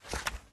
open_flip3.ogg